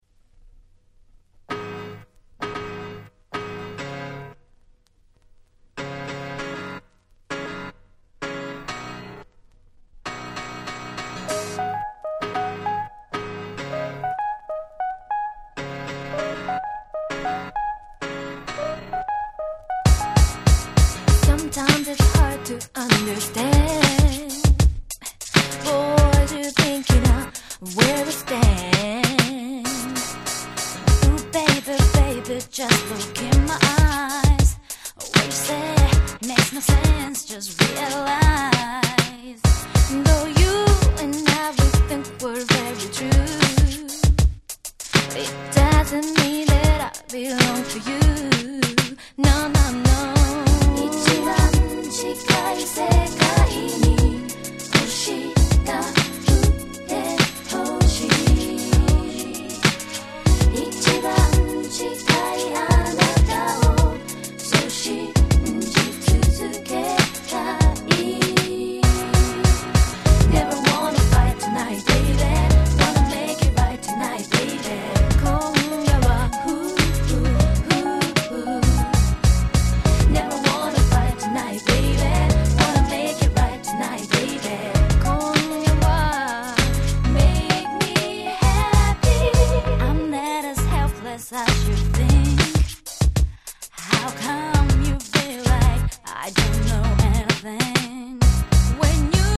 『Bed』、『Shake』同様に二人の華やかなコーラスが楽しめる1曲です。